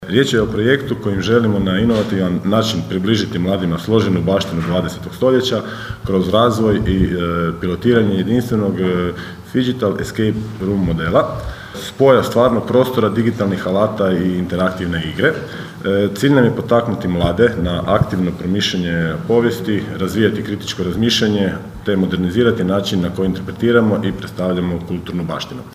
ton – Goran Vlačić), rekao je u uvodu zamjenik gradonačelnika Labina Goran Vlačić.